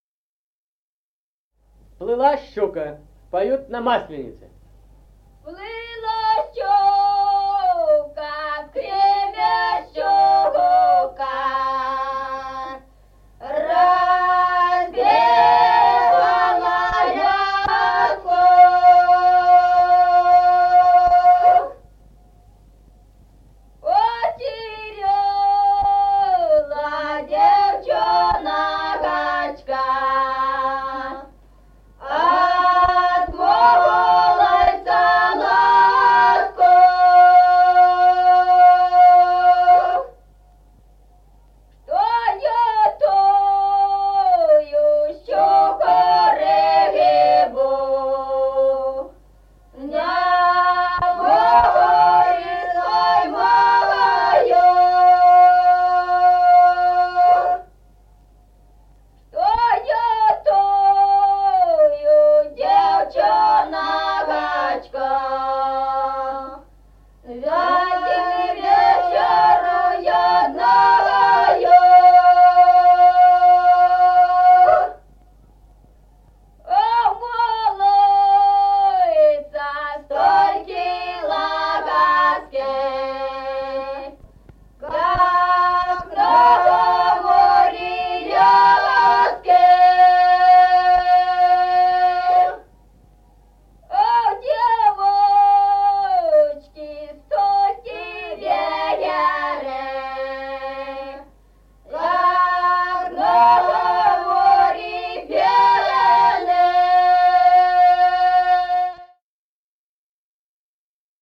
Народные песни Стародубского района «Плыла щука», масленичная.
(подголосник)
(запев).
1953 г., с. Мишковка.